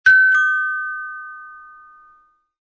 Carillon Fiesta 230V blanc
génération de son: mécanique
fonction complémentaire: son double
volume sonore: 75 dB